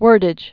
(wûrdĭj)